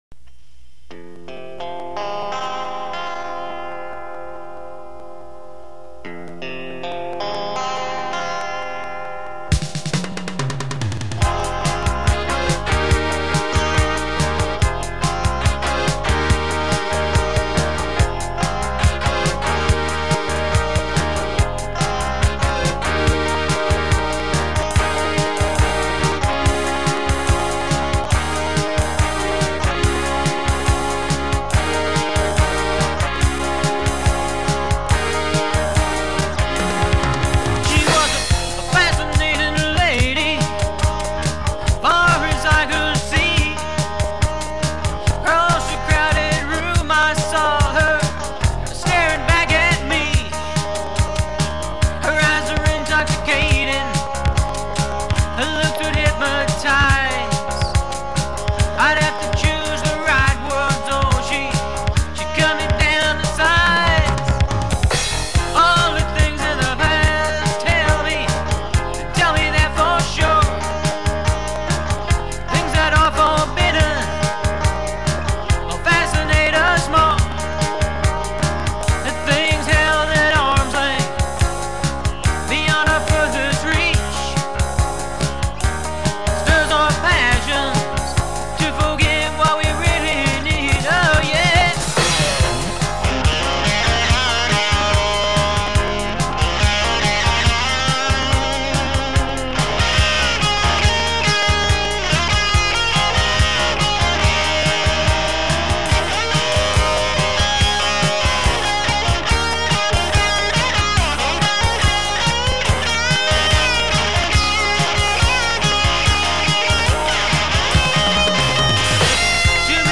Siren Song SQ80 Bass, Piano & Synthe parts-Drums are Roland 505-Guitar is a Frankenstein Strat I assembled from parts of several victims....same mix stated above.